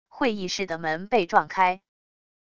会议室的门被撞开wav音频